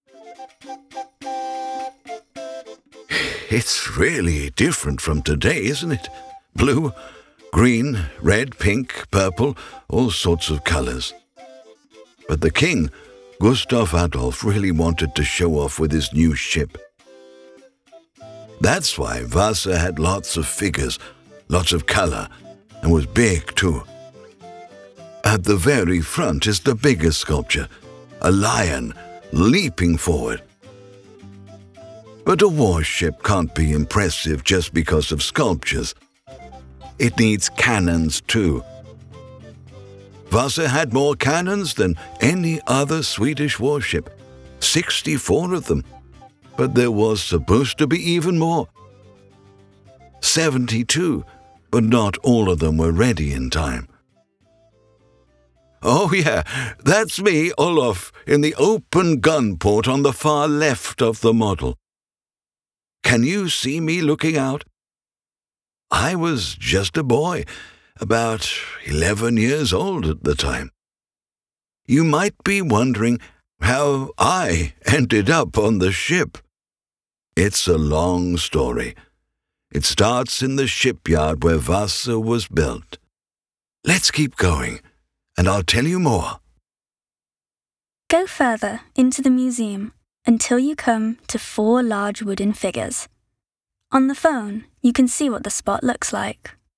English audio guide for children